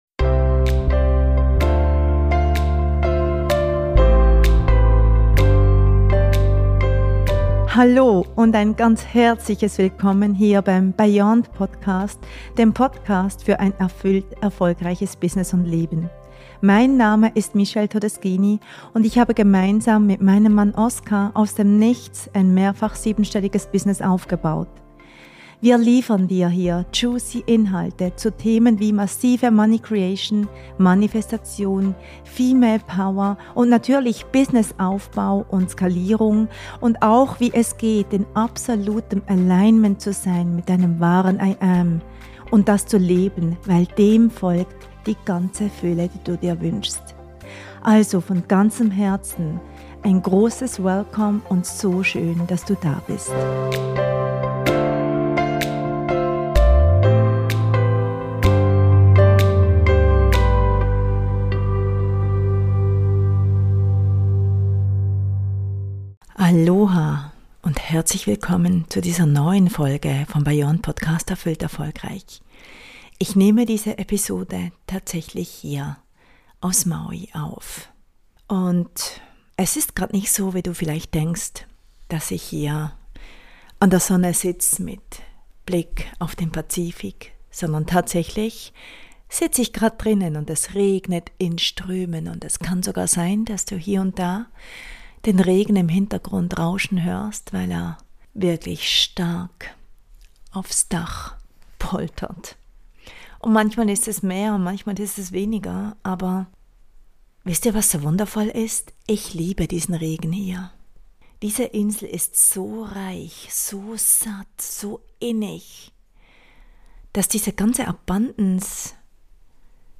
Aloha Diese Folge wurde auf Maui, Hawaii, aufgenommen.